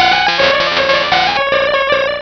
sovereignx/sound/direct_sound_samples/cries/jynx.aif at master